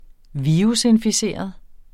Udtale [ -enfiˈseˀʌð ]